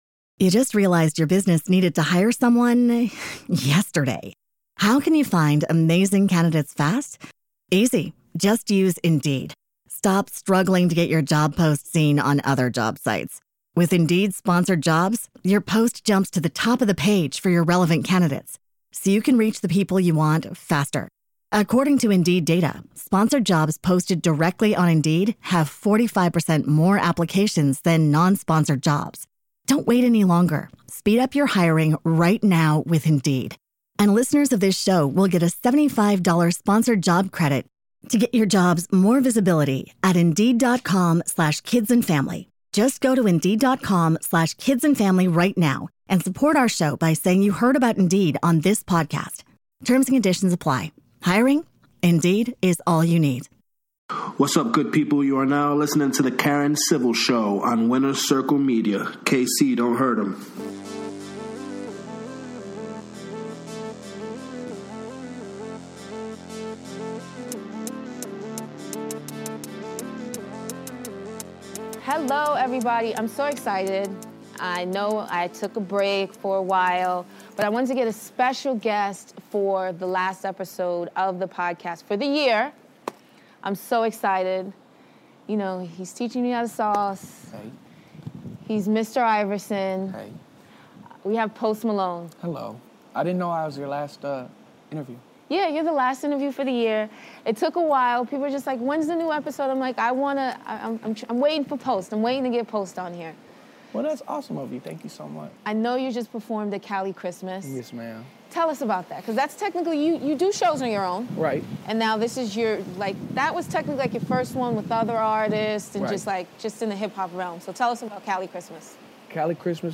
Episode 6: Post Malone Interview
On her final show of 2015 Karen Civil sits down for an interview with hip hop artist Post Malone as they discuss his latest work, his feelings on race in hip-hop, his holiday plans and much more!